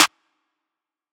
Clp (Lie to Me)_2.wav